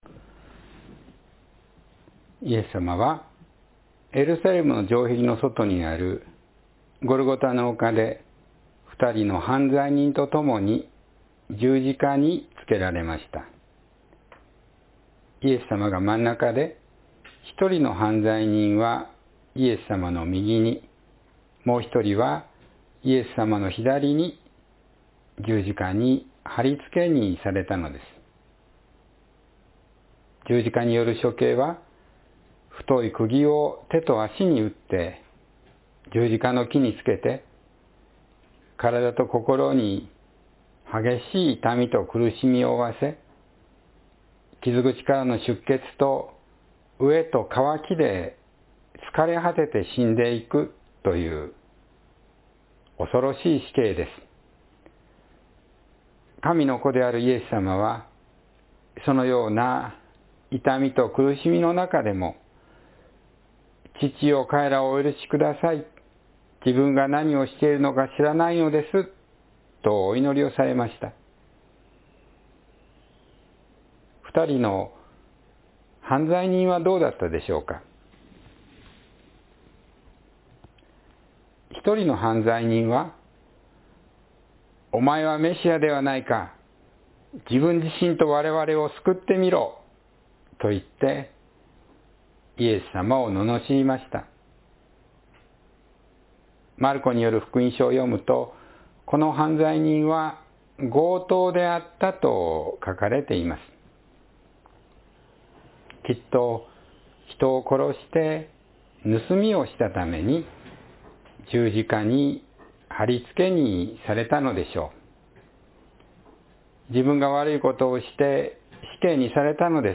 救われた犯罪人（2022年4月3日・子ども説教）